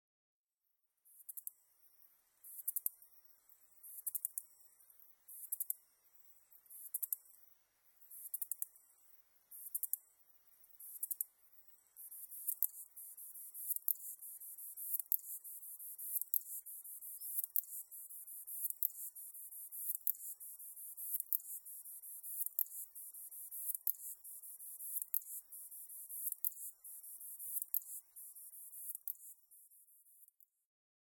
エゾツユムシ　Ducetia chinensisキリギリス科
日光市稲荷川中流　alt=730m  HiFi --------------
Rec.: EDIROL R-09
Mic.: Sound Professionals SP-TFB-2  Binaural Souce
他の自然音：　 ヤマヤブキリ